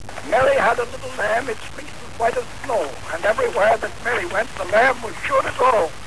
Thomas Alva Edison i njegov pomoćnik John Kruesi uzbuđeno su slušali škripavi zvuk koji je dolazio s rotirajućeg valjka FONOGRAFA. Samo minutu, dvije, prije Edison je u metalnu cijev svoje govorne mašine recitirao:
To su bile prve riječi koje su zapisane na neki nosač zvuka i poslije uspješno reproducirane.